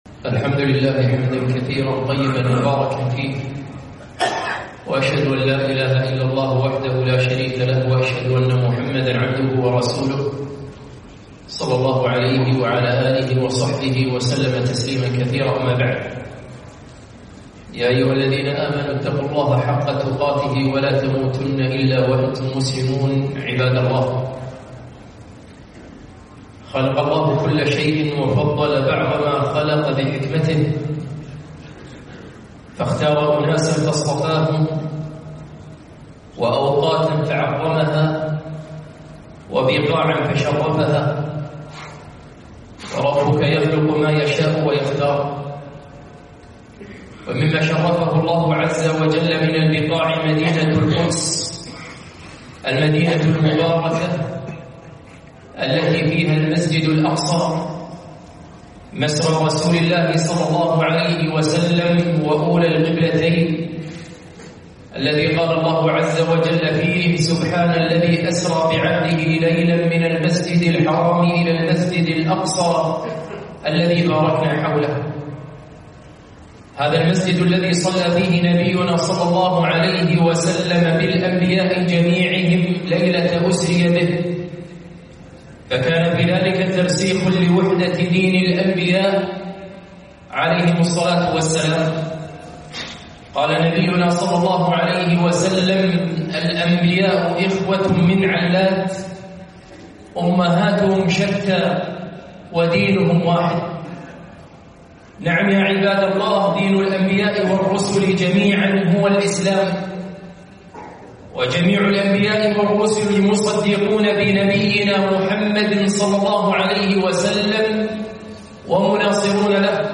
خطبة - تاريخ المسجد الأقصى